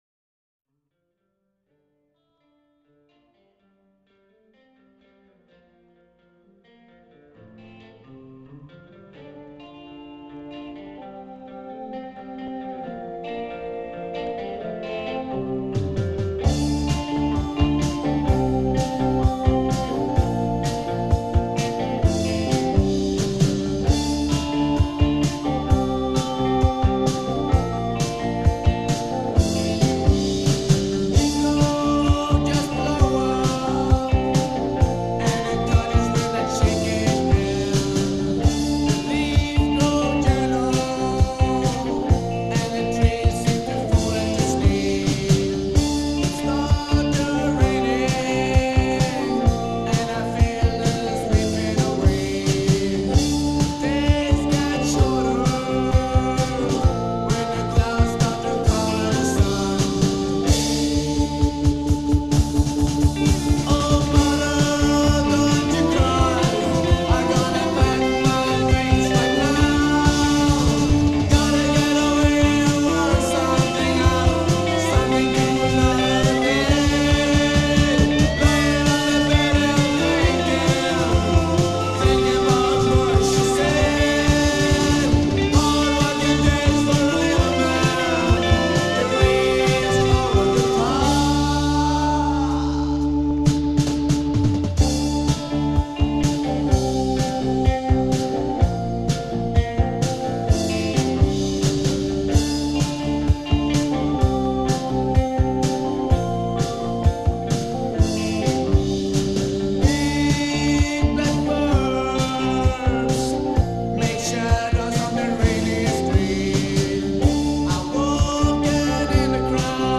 March 13-14, 1982 (From pure old vinyl record)